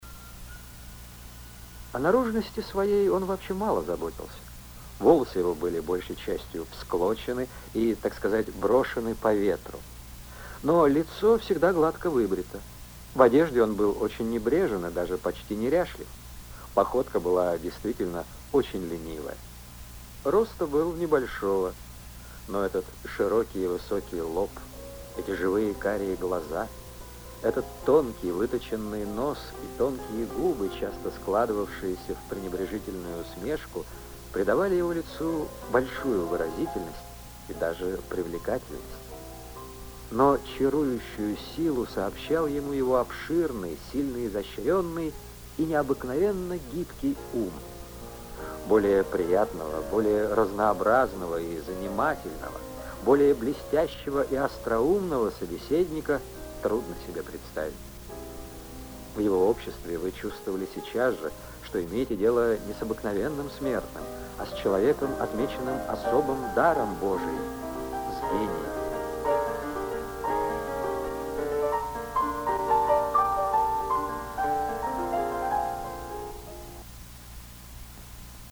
Звучит аудиозапись воспоминаний о Тютчеве (